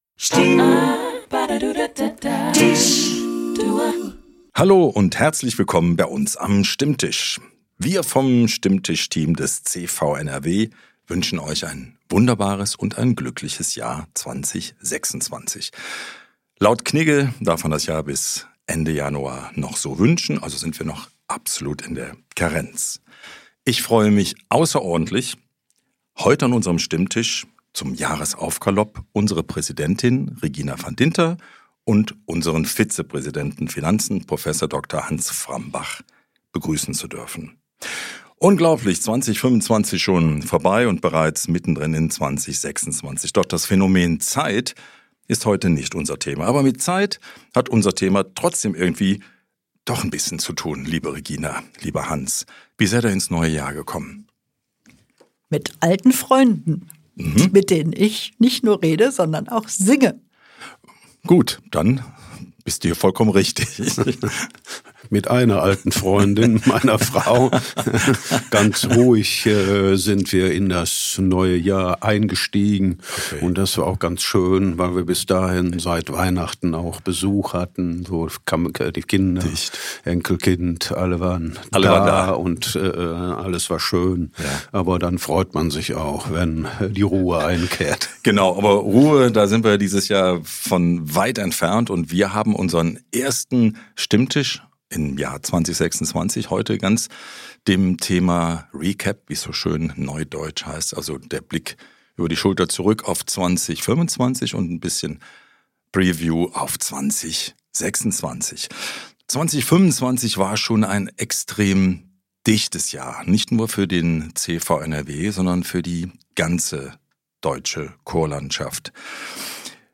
Wir sprechen mit vielfältigen Gästen über gemeinsames Singen – für Gemeinschaft, Bildung und Kultur.